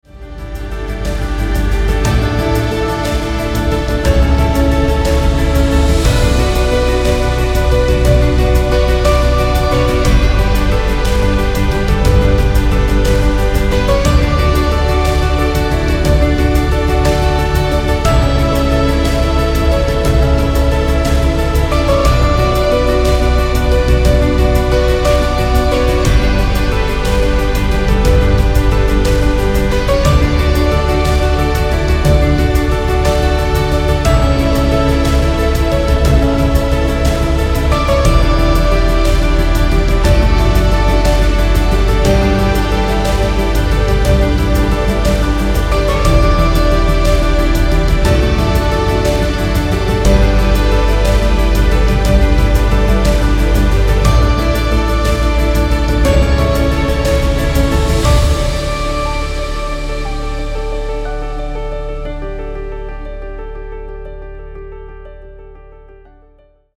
Binaurale Theta Meditationsmusik – Dauer 05:07 Minuten